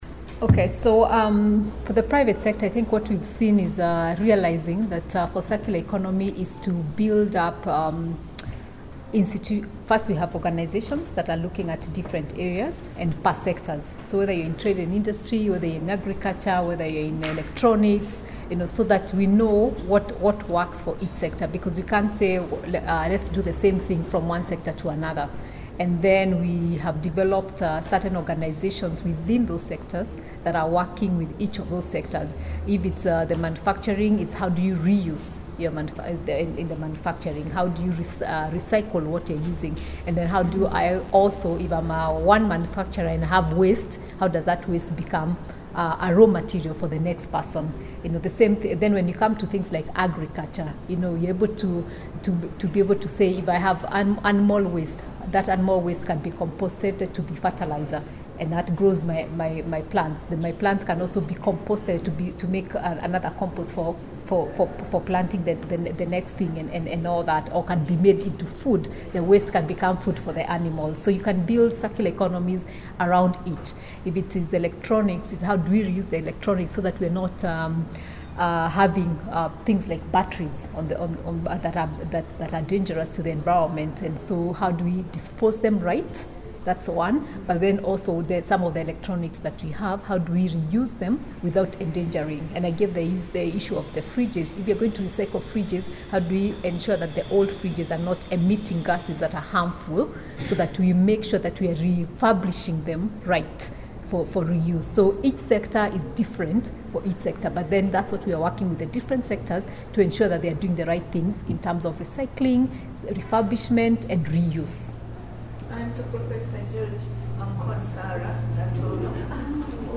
Q-AND-A-SESSION-press-conference-8th-conf-on-CE.mp3